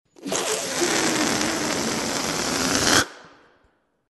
Звуки шариков
Шум сдувающегося шарика с выходящим воздухом